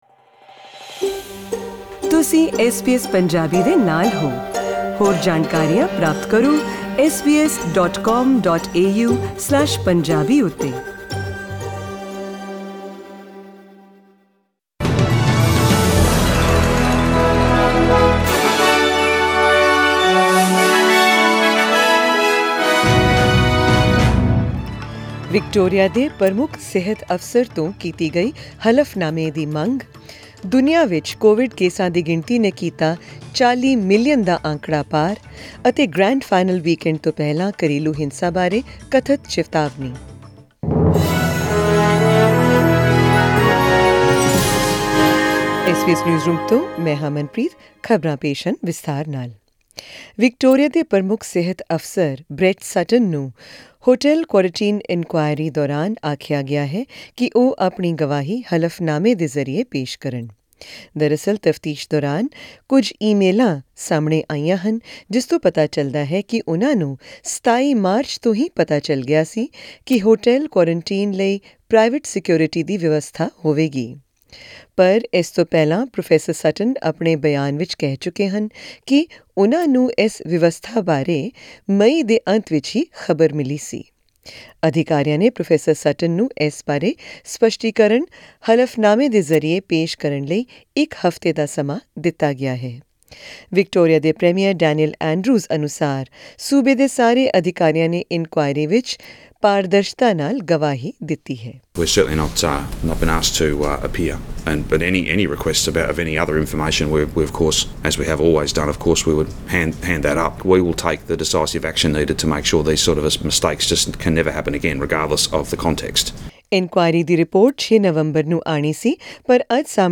In tonight's news bulletin, hear about the sobering worldwide landmark for COVID-19 infections, know about Prime Minister Scott Morrison's answer to questions about any involvement with disgraced former NSW Member of Parliament's cash for visa scam, and the family violence warning before AFL and NRL Grand Final weekend.